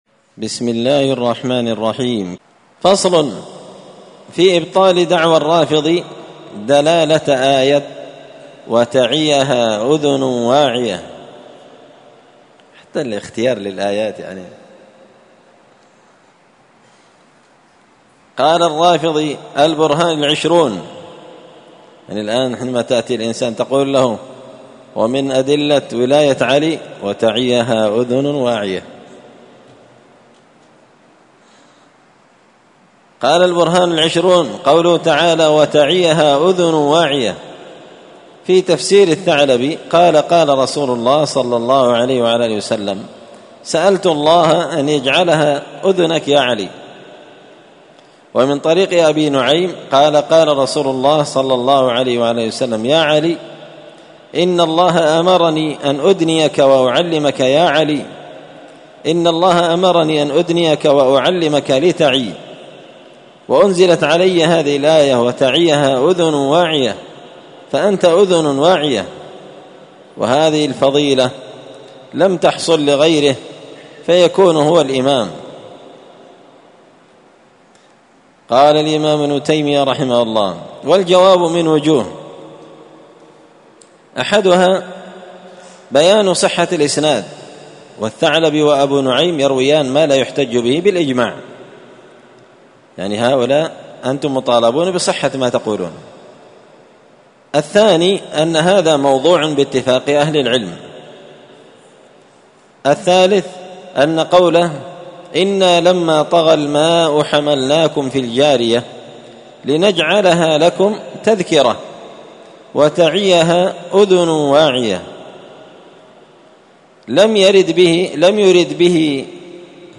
الأثنين 12 صفر 1445 هــــ | الدروس، دروس الردود، مختصر منهاج السنة النبوية لشيخ الإسلام ابن تيمية | شارك بتعليقك | 85 المشاهدات
مسجد الفرقان قشن_المهرة_اليمن